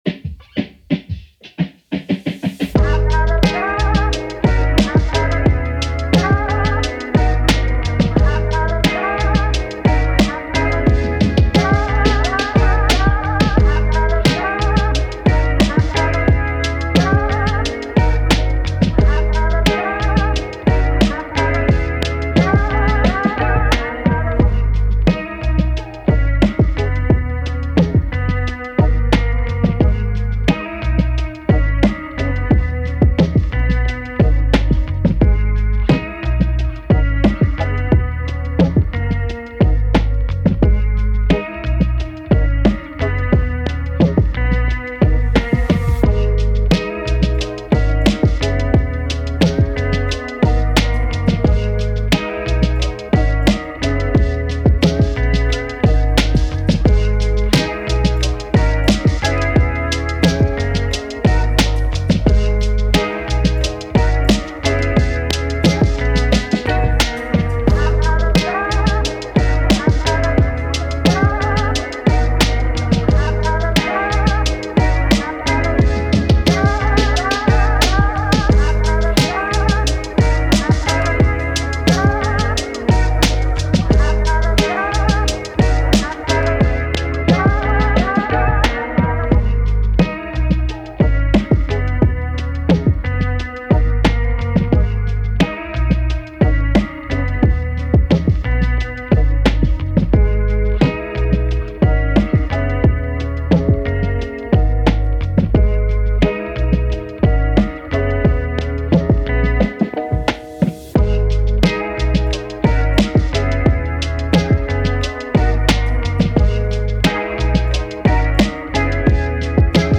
Hip Hop, 80s, 90s, Lofi
Abm